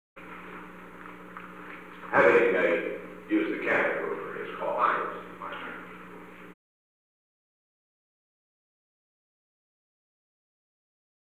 Secret White House Tapes
Conversation No. 906-18
Location: Oval Office
The President met with an unknown man.